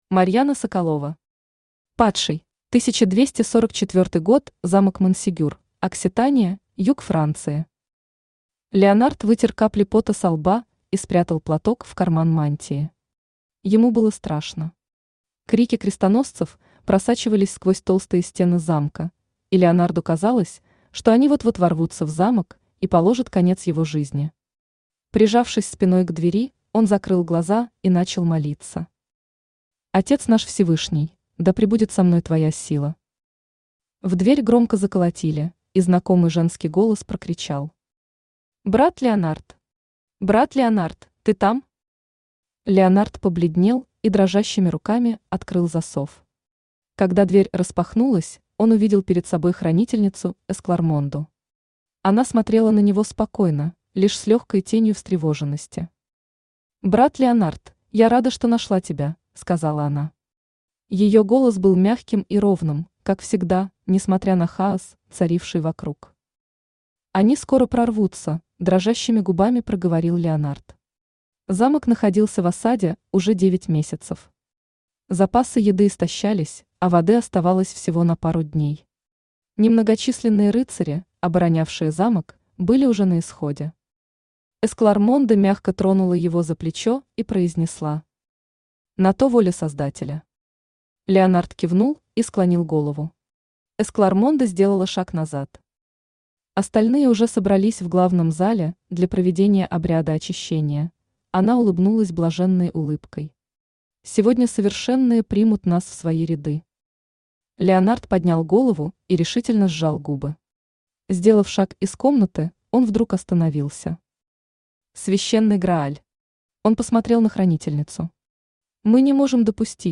Aудиокнига Падший Автор Марьяна Соколова Читает аудиокнигу Авточтец ЛитРес.